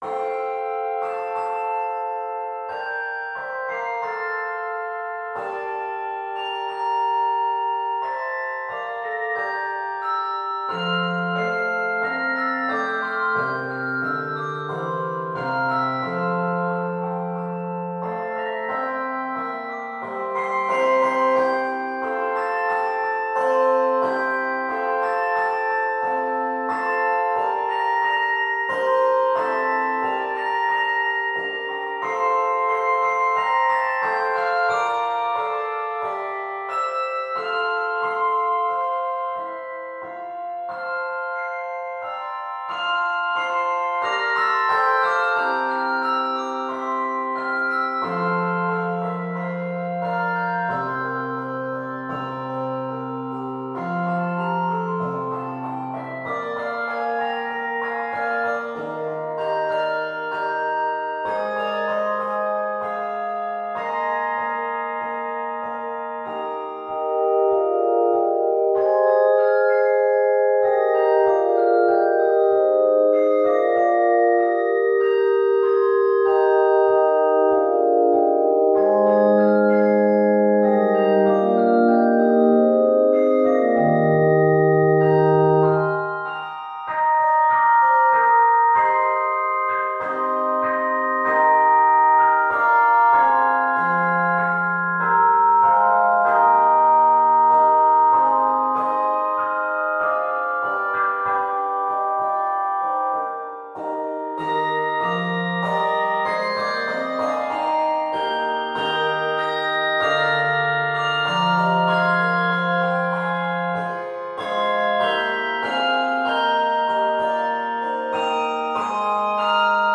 Keys of F Major and Eb Major.